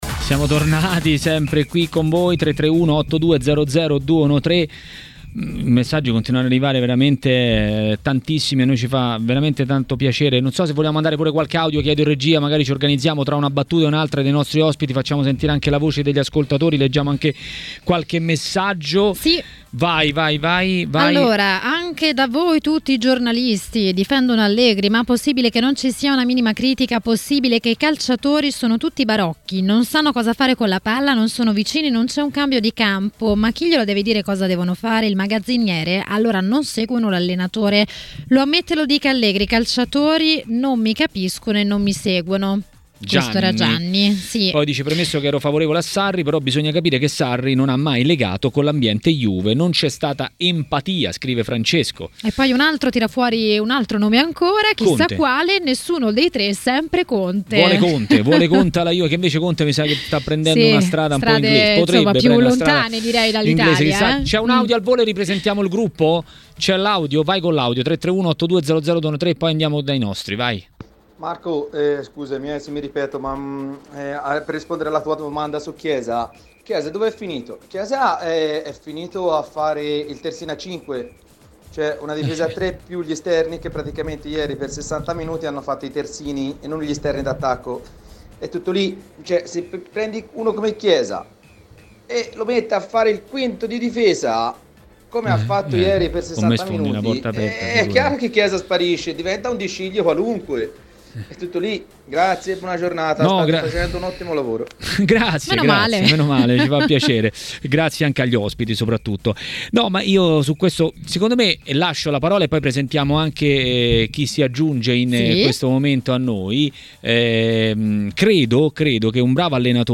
A TMW Radio, durante Maracanà, è intervenuto l'ex calciatore Antonio Paganin per parlare del momento dell'Inter.